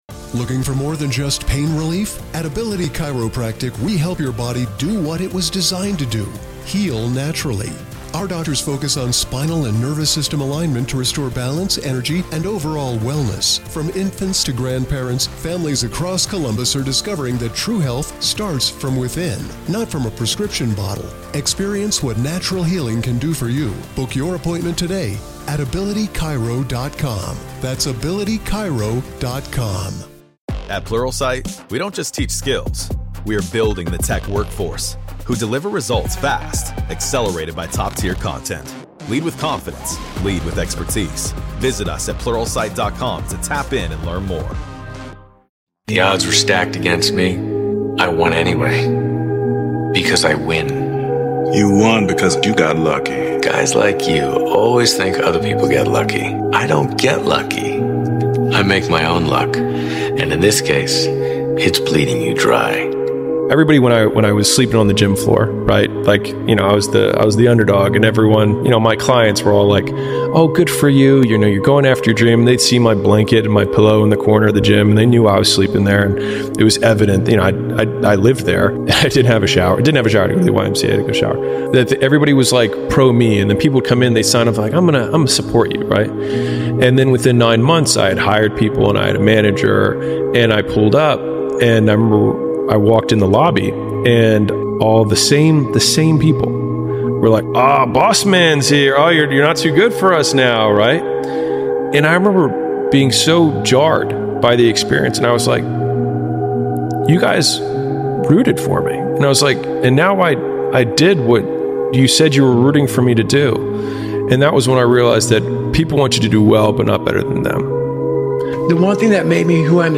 Get ready to be inspired by the words of David Goggins—one of the toughest voices in motivation. With a focus on resilience, determination, and relentless pursuit of greatness, this David Goggins motivational speech compilation will push you to your limits.